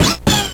Sound effect of Yoshi Ride in Yoshi's Island DS.